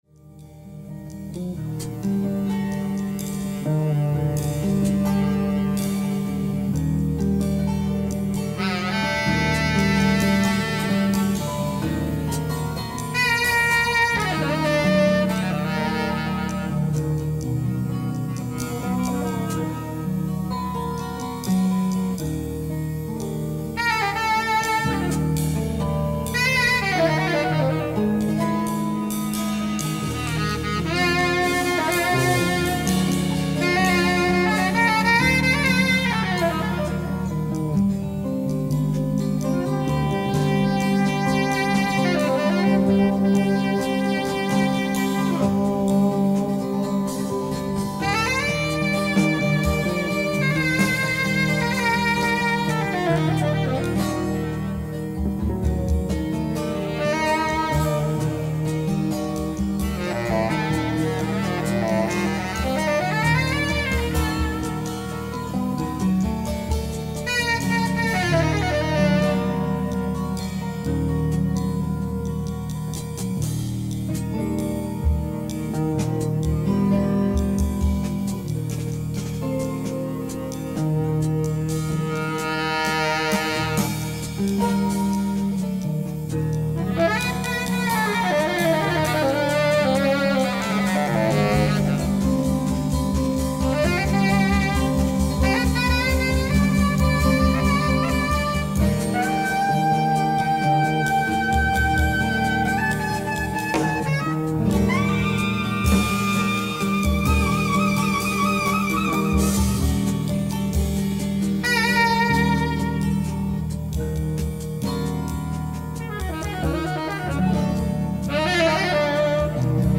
ライブ・アット・モルデ・ジャズフェスティバル、モルデ、ノルウェー 1978
※試聴用に実際より音質を落としています。